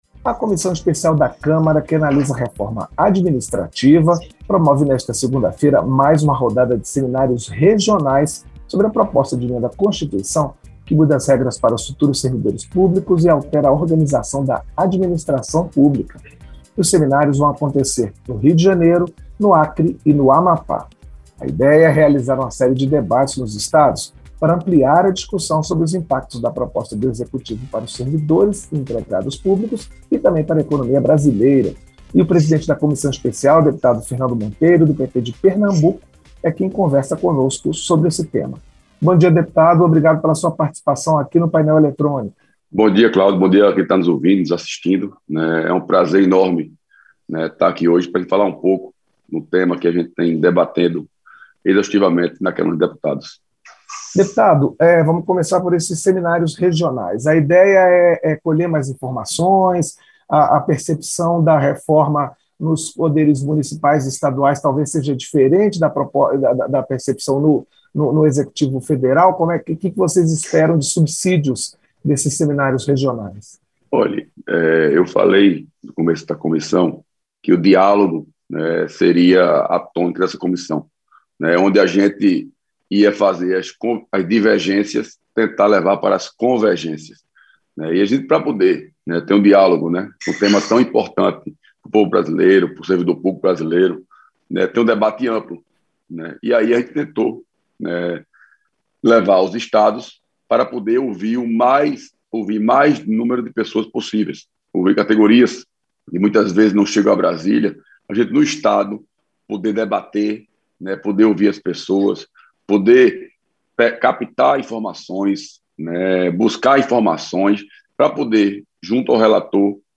Entrevista - Dep. Fernando Monteiro (PP-PE)